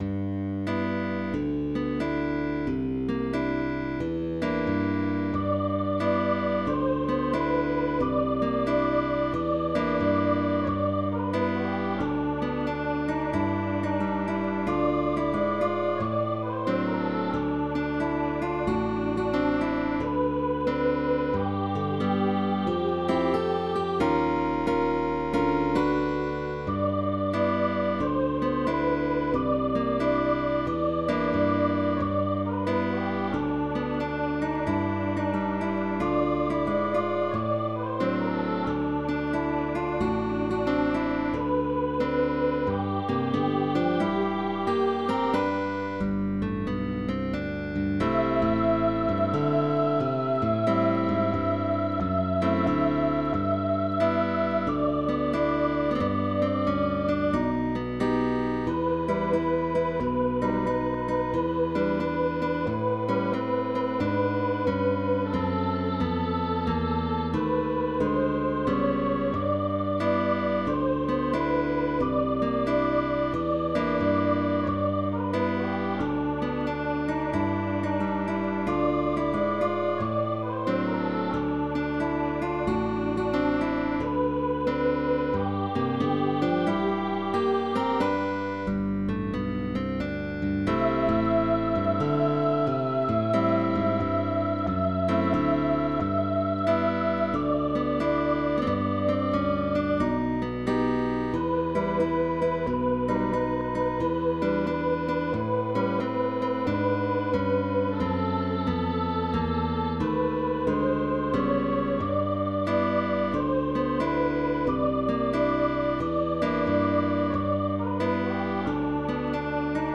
para voz y guitarra.